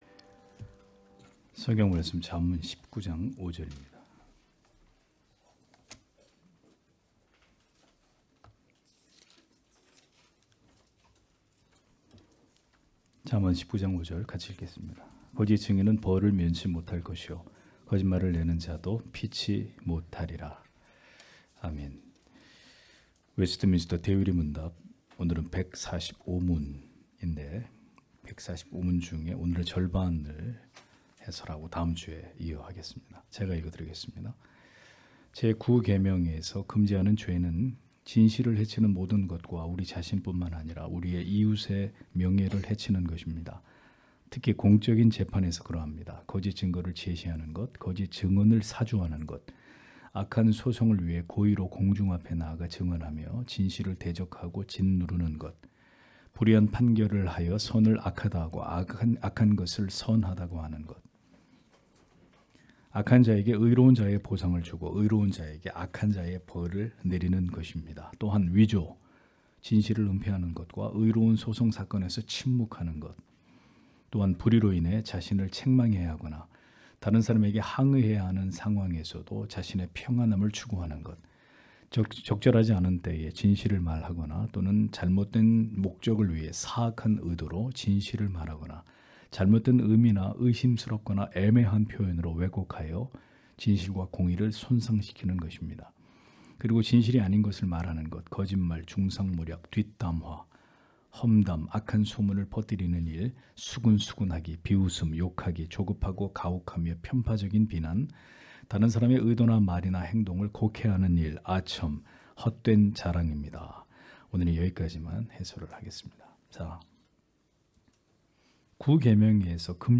주일오후예배 - [웨스트민스터 대요리문답 해설 145 (1)] 145문) 제 9계명에서 금지하는 죄는 무엇인가?
* 설교 파일을 다운 받으시려면 아래 설교 제목을 클릭해서 다운 받으시면 됩니다.